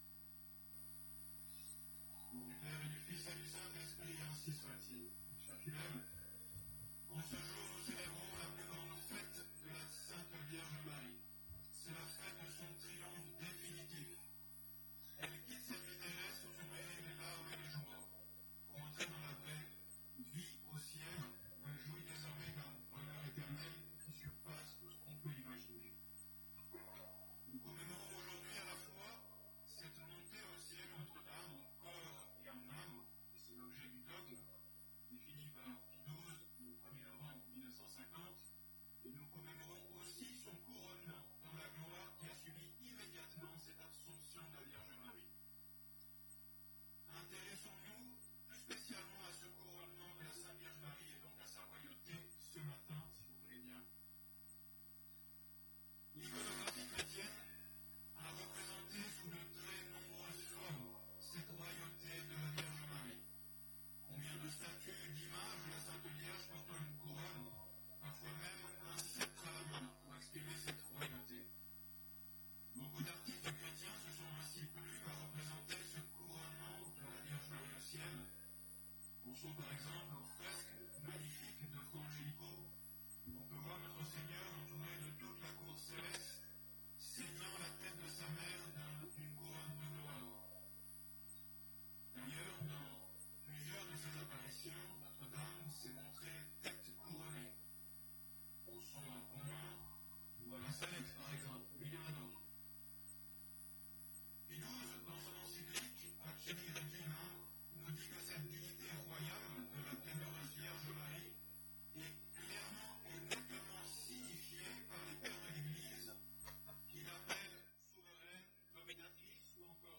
Type: Sermons Occasion: Assomption